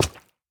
minecraft / sounds / dig / coral1.ogg
coral1.ogg